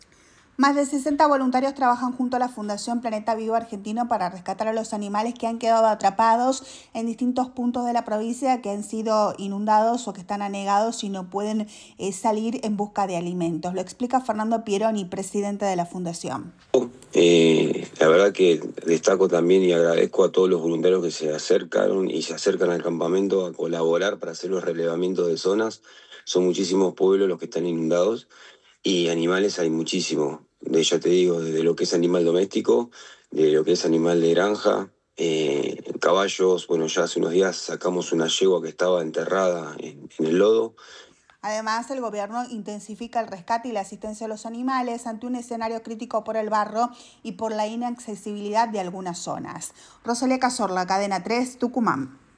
El director de la Agencia Federal de Emergencias defendió en Cadena 3 Rosario el despliegue nacional, detalló el trabajo con medios aéreos y brigadistas en Chubut y explicó que el DNU permite reforzar recursos ante un escenario climático extremo.